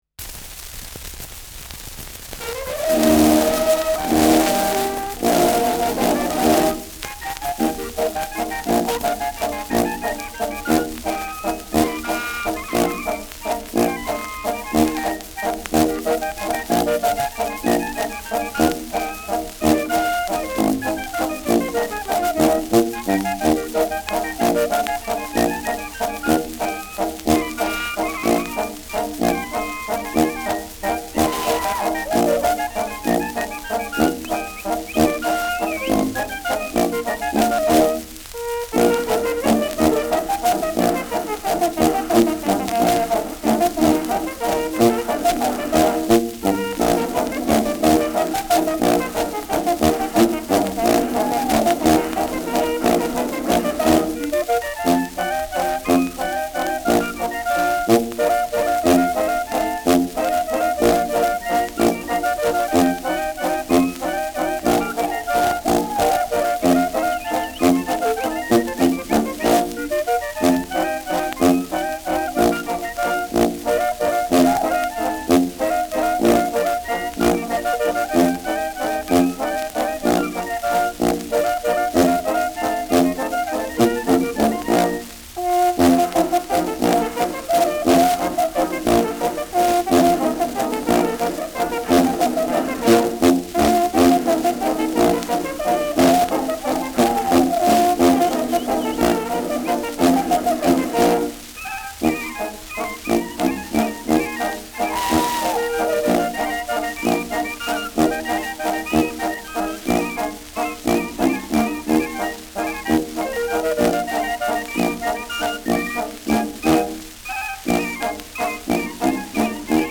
Schellackplatte
präsentes Rauschen : präsentes Knistern : abgespielt : „Schnarren“ : leiert
Dachauer Bauernkapelle (Interpretation)
Mit Juchzern und Pfiffen.